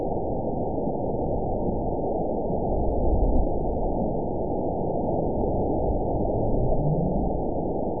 event 913778 date 04/19/22 time 22:32:39 GMT (3 years ago) score 9.35 location TSS-AB04 detected by nrw target species NRW annotations +NRW Spectrogram: Frequency (kHz) vs. Time (s) audio not available .wav